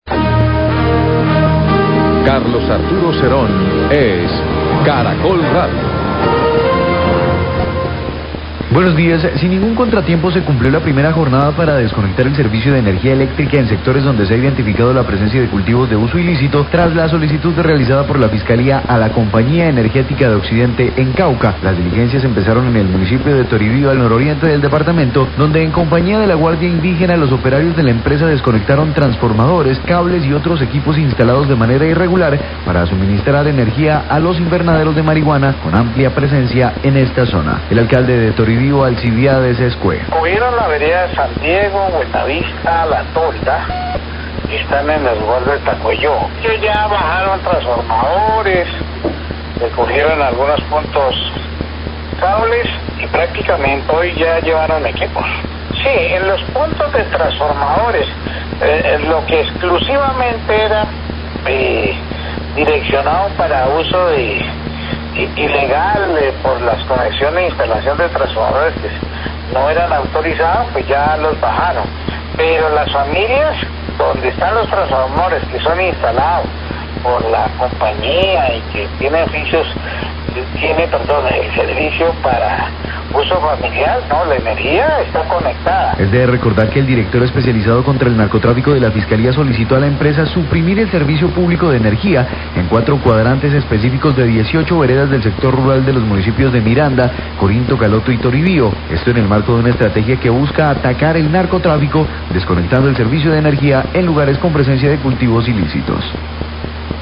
Radio
Se cumplió la primera jornada de suspensión del servicio de energía donde hay presencia de invernaderos de marihuana tras la solicitud de la Fiscalía a la Compañía Energética. En Toribío y en compañía de la guardia indígena, los operarios desconectaron transformadores, cables y equipos instalados irregularmente. Declaraciones del Alcalde de Toribío, Alcibiades Escúe.